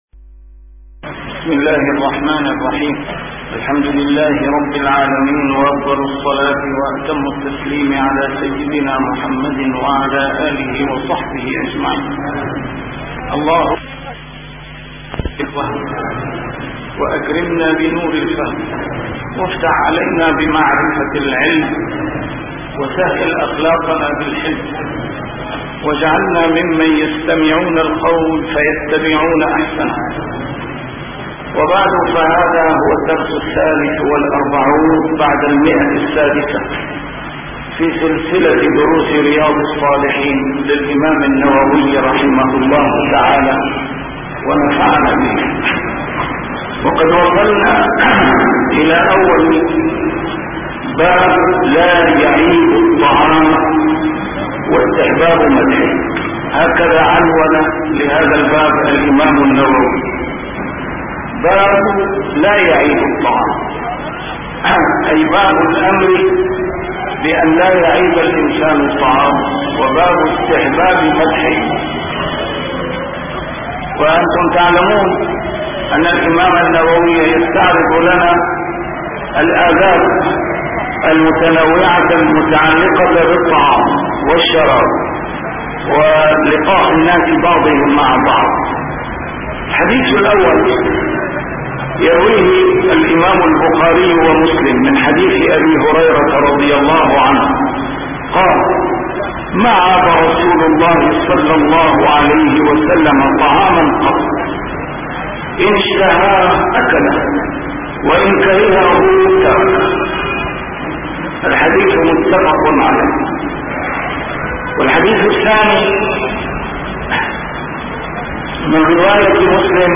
A MARTYR SCHOLAR: IMAM MUHAMMAD SAEED RAMADAN AL-BOUTI - الدروس العلمية - شرح كتاب رياض الصالحين - 643- شرح رياض الصالحين: لا يعيب الطعام واستحباب مدحه